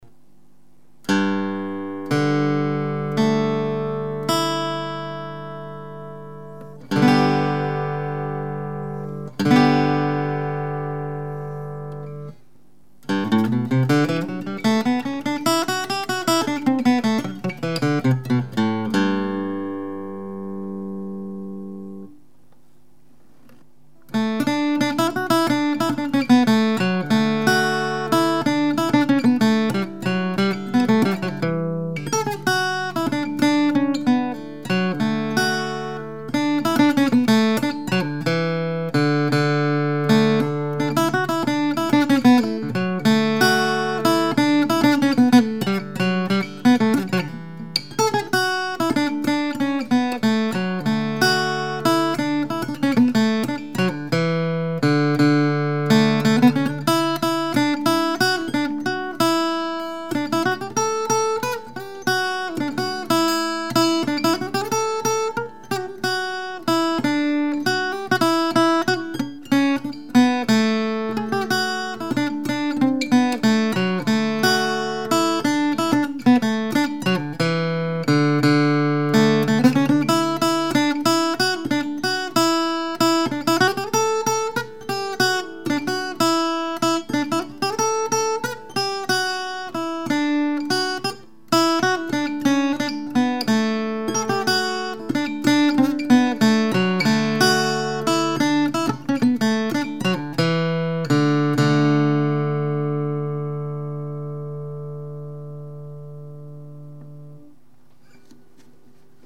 Tenor guitar, tuned GDAE an octave below the mandolin, but other tunings are possible with different strings.
Listen to a small body 23inch scale length.